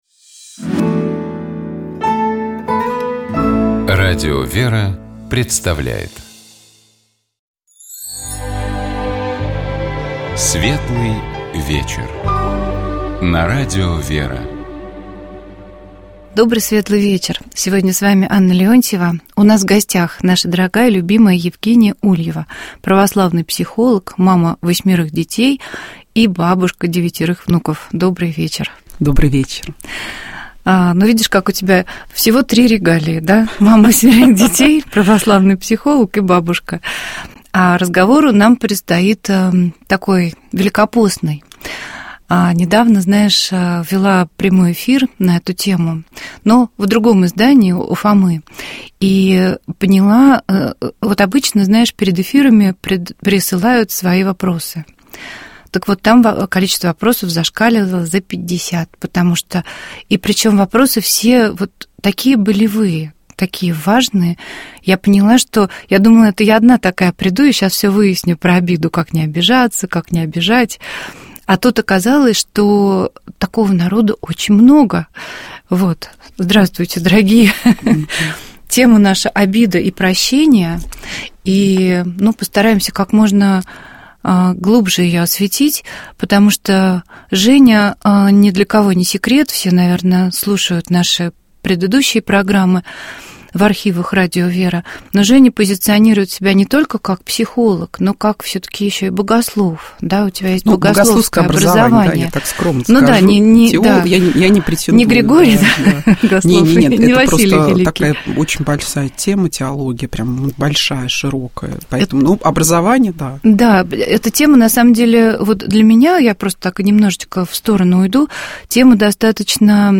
Тексты богослужений праздничных и воскресных дней. Часы воскресного дня. 9 марта 2025г.